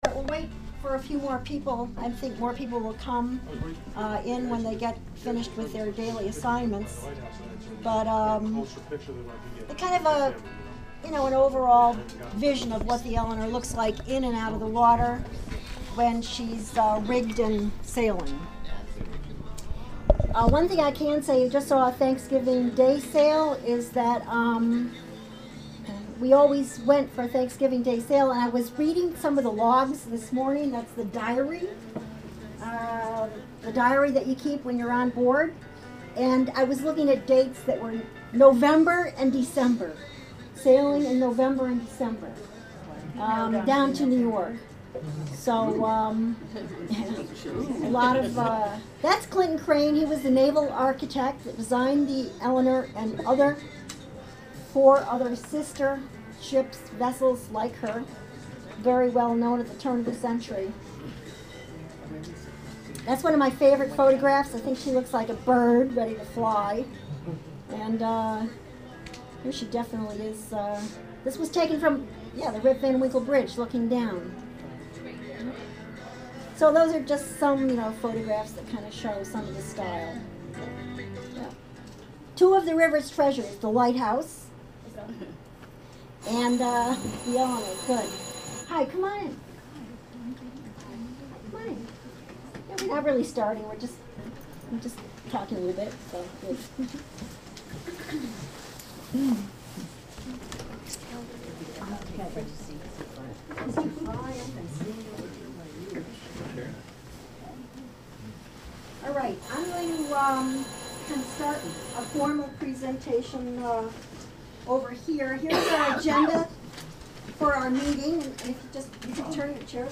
Sloop Eleanor Informational Meeting: Jan 06, 2011: 5pm- 11:59 pm